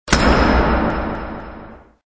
1 channel
40_canSound.mp3